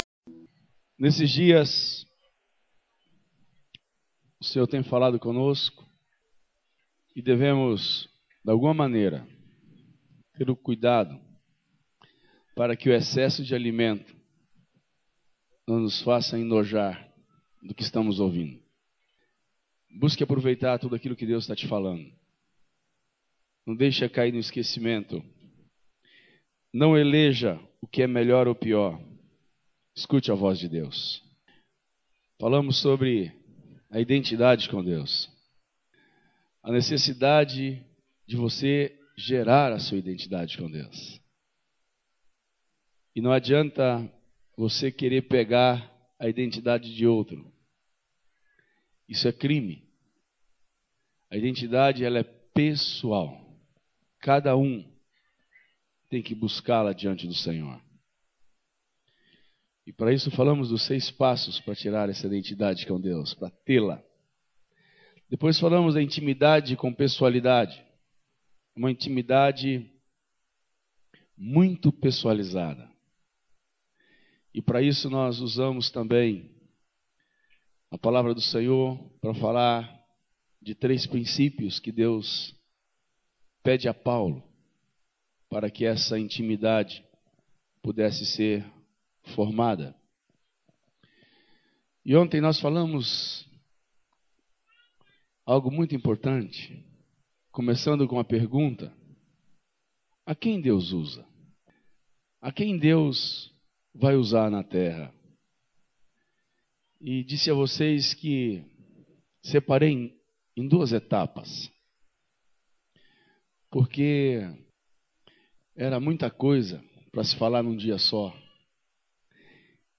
Ministrações